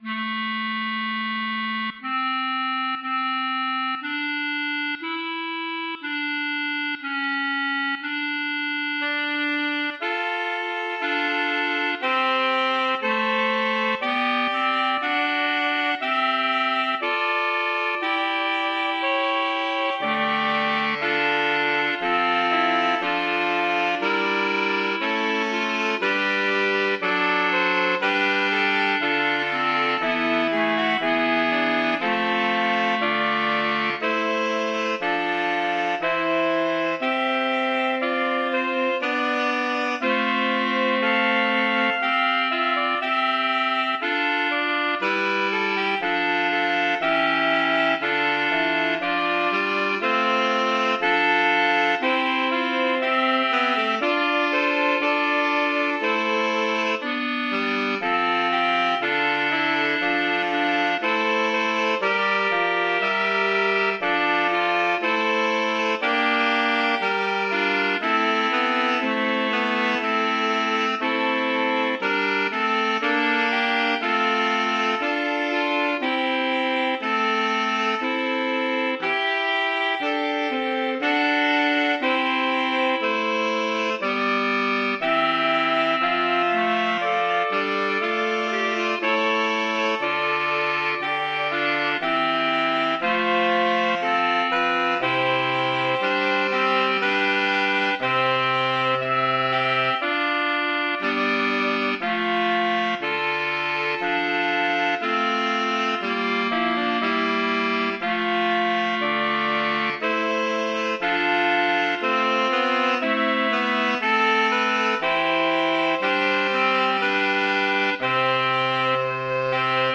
Number of voices: 4vv Voicing: SATB Genre: Sacred, Motet